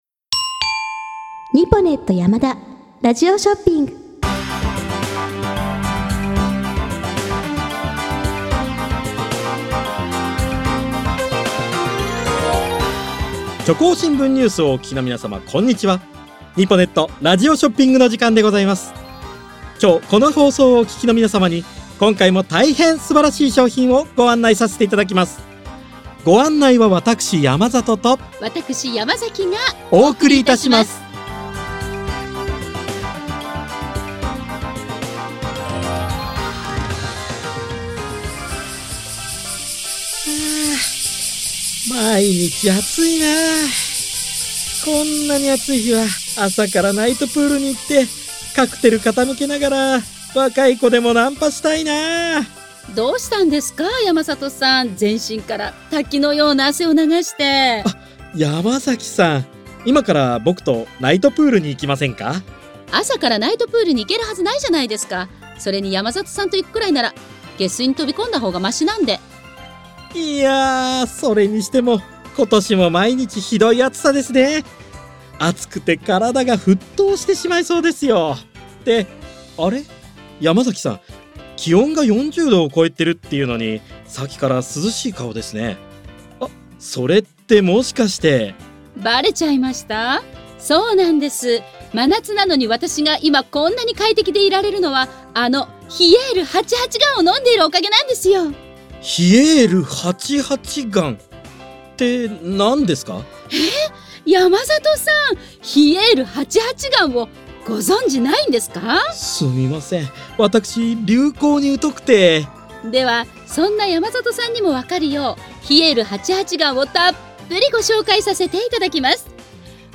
この番組は、他の新聞メディアにはない独自の取材網を持っている虚構新聞社がお届けする音声ニュース番組「虚構新聞ニュース」です。